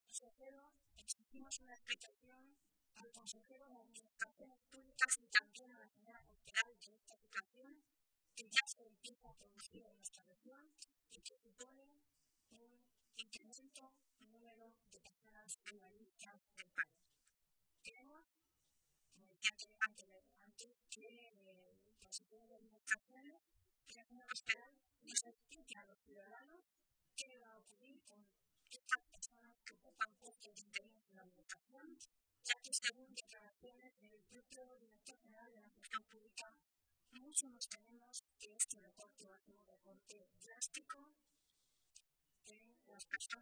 Milagros Tolón, portavoz de Empleo del Grupo Socialista
Cortes de audio de la rueda de prensa